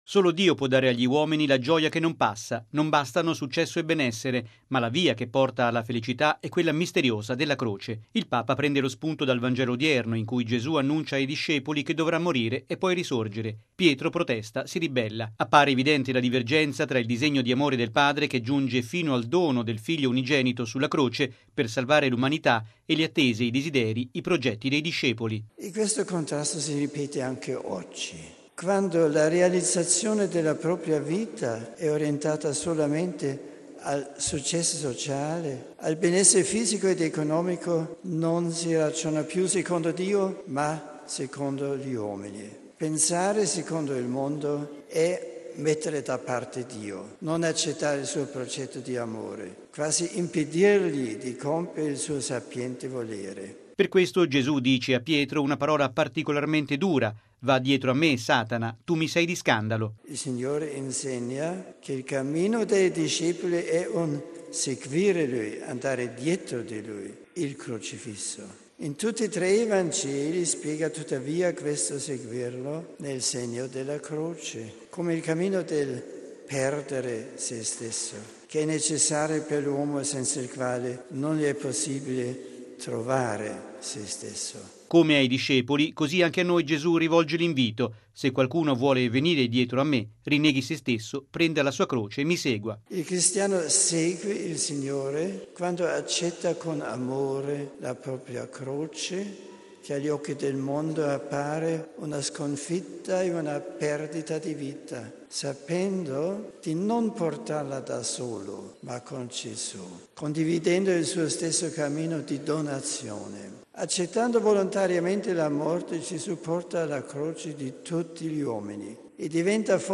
Grande l'entusiasmo dei pellegrini presenti.